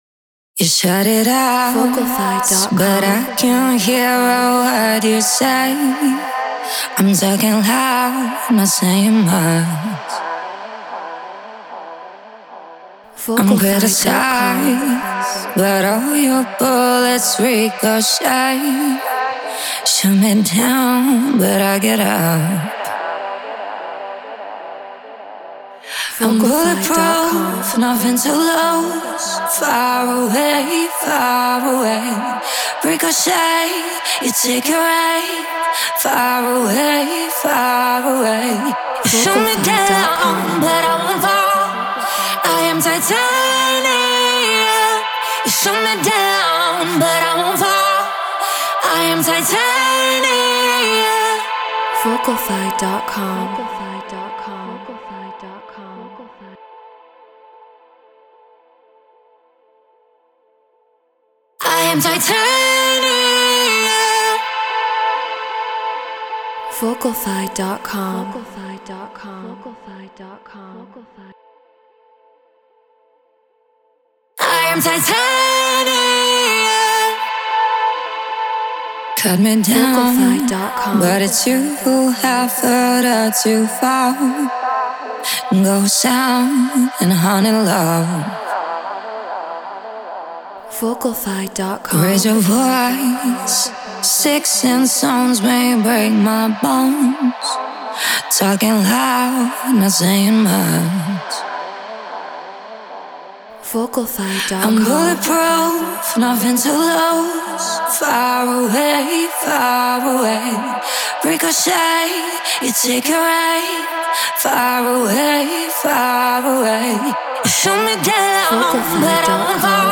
Hardstyle 150 BPM Amin
Our vocalists and producers covered the original song.